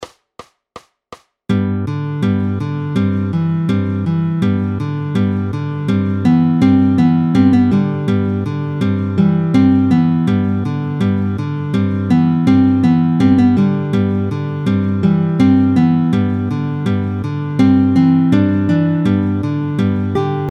démo guitare